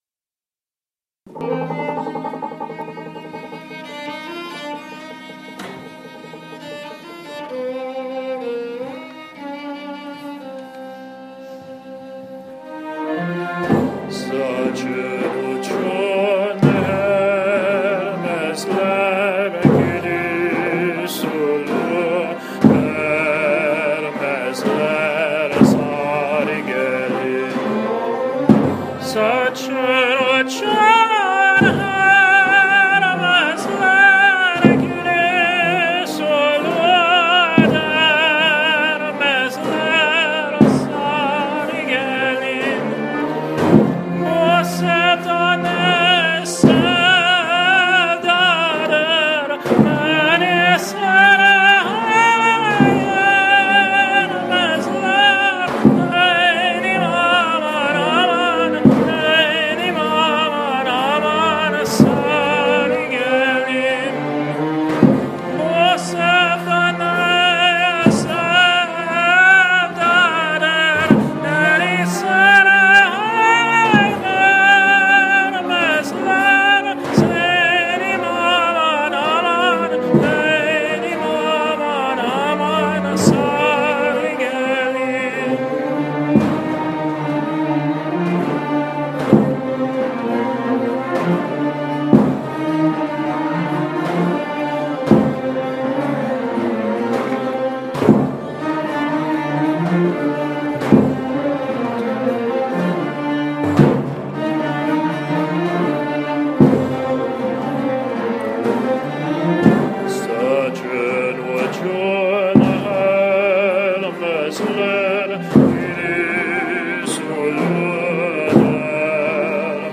Sari Gelin, a traditional Central Asian song (Azeri version)
with the University of Chicago’s Middle Eastern Music Ensemble (a 35-piece orchestra)